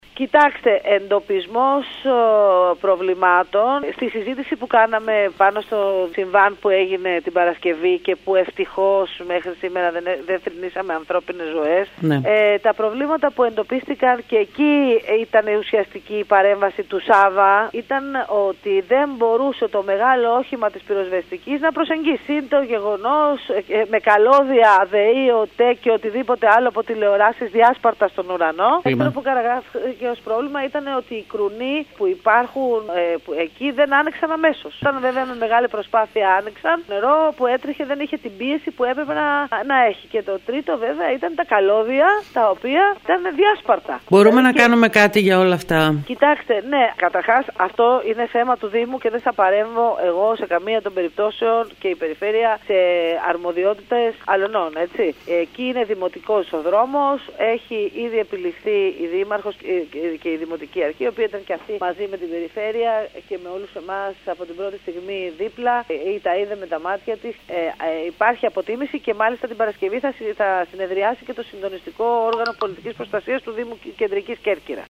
Σύμφωνα με τα όσα δήλωσε σήμερα στο σταθμό μας η Αντιπεριφερειάρχης Μελίτα Ανδριώτη δεν θα εμπλακεί σε θέματα αρμοδιότητας του Δήμου.